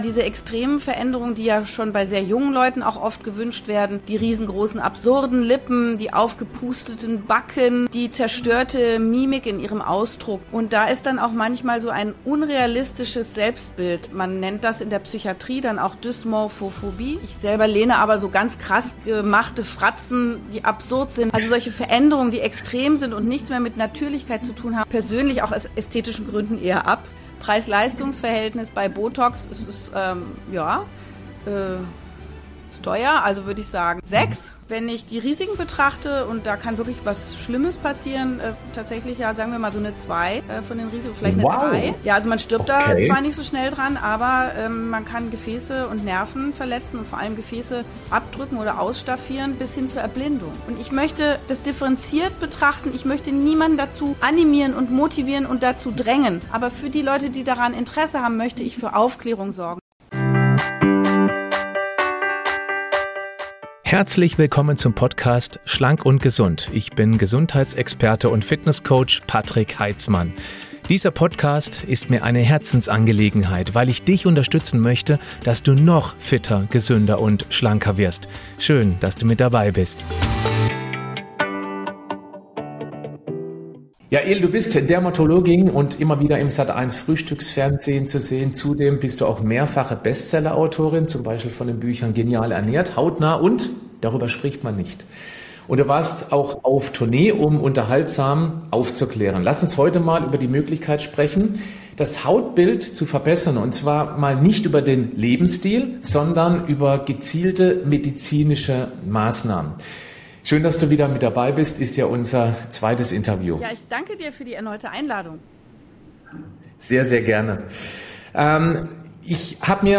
Botox, Hyaluron & Co. – Interview mit Dr. Yael Adler über Schönheitsbehandlungen✨ In dieser Folge spreche ich mit der renommierten Hautärztin Dr. Yael Adler über die Welt der Schönheitsbehandlungen. Wir tauchen tief in Themen wie Botox, Hyaluron und viele weitere Eingriffe ein, die immer häufiger von Menschen genutzt werden, um jünger oder frischer auszusehen. Wir gehen auf altbewährte, aber auch neuartige Behandlungsformen und auf deren Kosten-Nutzen sowie auf das Preis-Leistungsverhältnis ein.